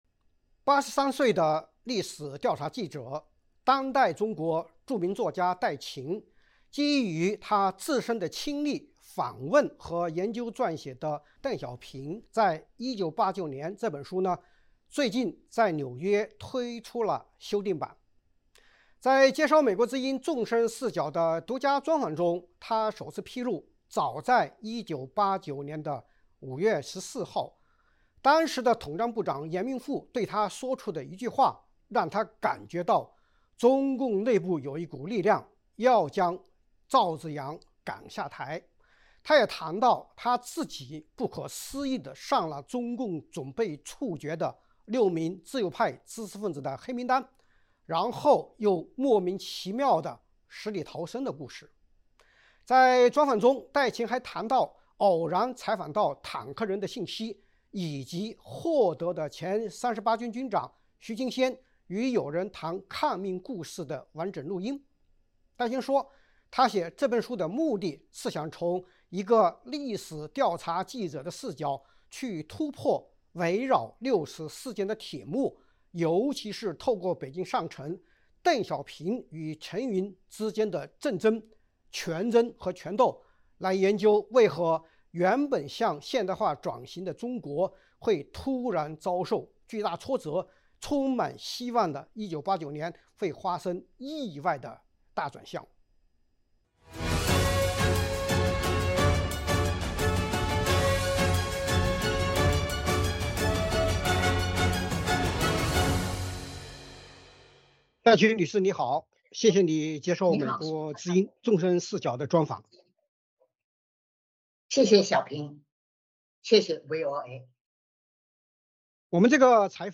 专访戴晴 ：六四事件铁幕背后：邓小平陈云权力斗争
在接受美国之音《纵深视角》的独家专访中，她首次披露，早在89年5月14日，时任统战部长阎明复对她说出的一句话，让她感觉到中共内部有一股力量要赵紫阳下台；她也谈到自己上了中共准备处决的6名自由派知识分子名单，然后死里逃生的故事。